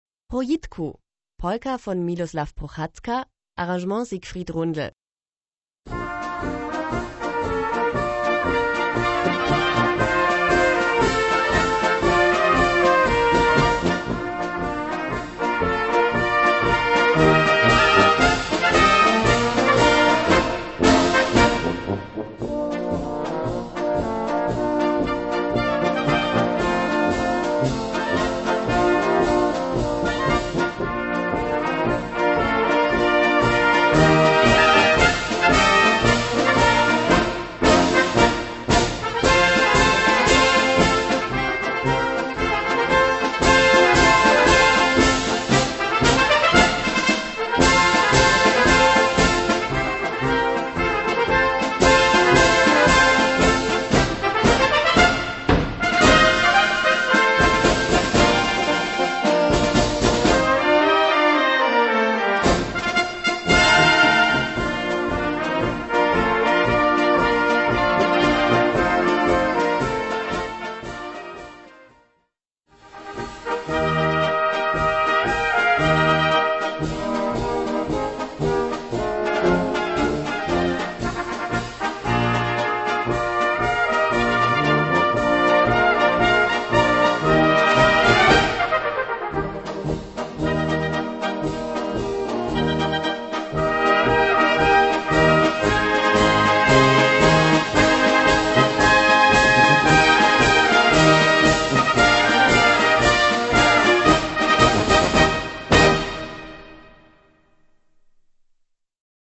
Gattung: Mährische Polka
Besetzung: Blasorchester